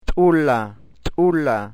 Glottalized Oclusives p'